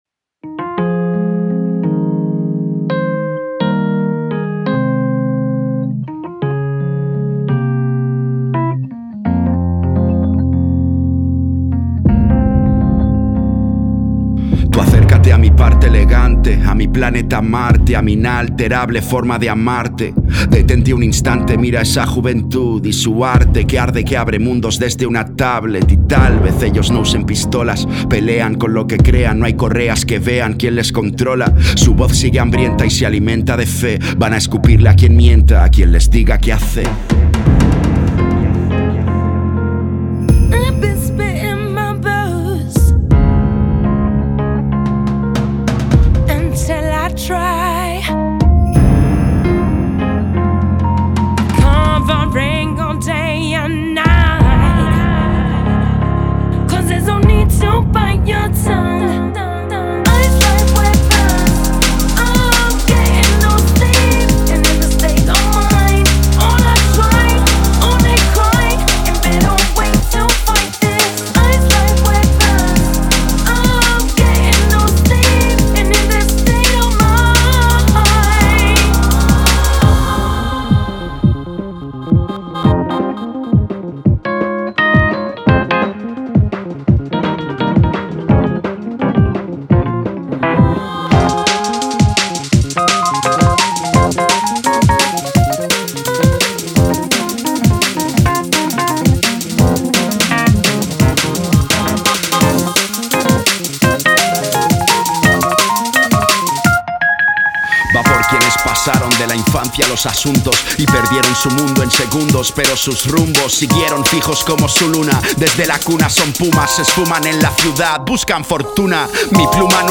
Жанр: Alternativa.